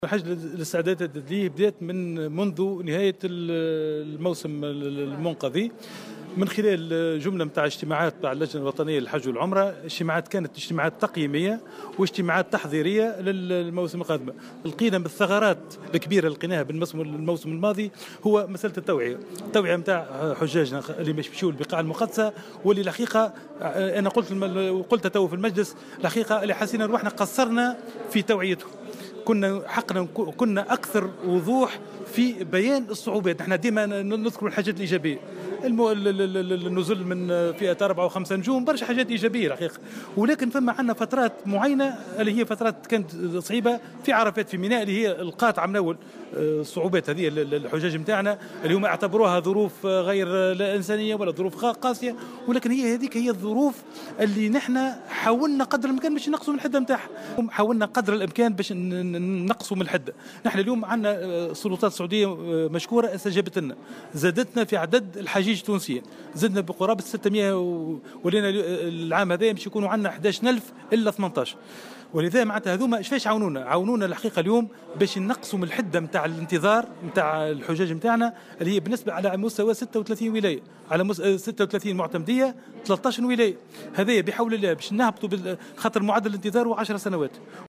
وأقر الوزير في تصريح لمراسل "الجوهرة أف أم" بوجود تقصير من حيث توعية الحجيج خلال الموسم الفارط، مشيرا إلى أنه سيتم العمل مستقبلا على تلافي هذه مثل النقائص والتقليص منها.